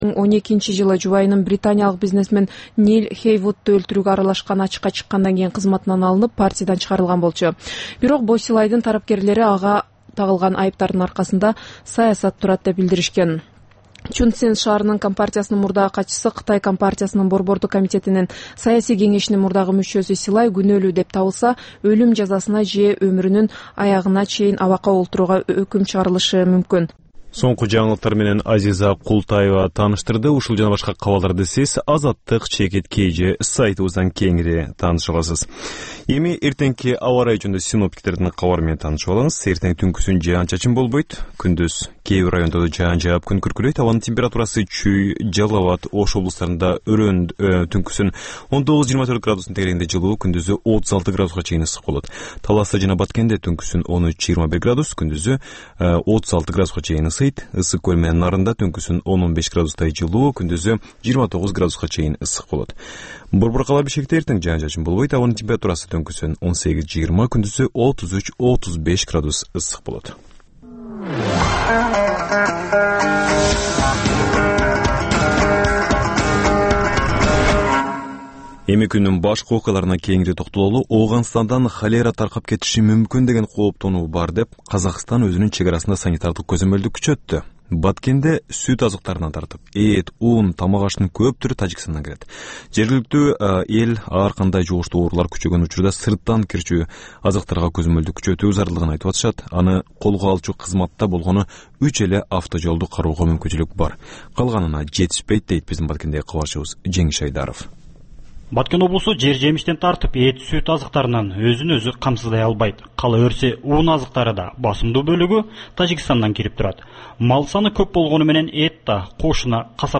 "Азаттык үналгысынын" бул кечки алгачкы жарым сааттык берүүсү жергиликтүү жана эл аралык кабарлар, репортаж, маек, аналитикалык баян, сереп, угармандардын ой-пикирлери, окурмандардын э-кат аркылуу келген пикирлеринин жалпыламасы жана башка берүүлөрдөн турат. Бул үналгы берүү ар күнү Бишкек убакыты боюнча саат 18:00ден 18:30га чейин обого түз чыгат.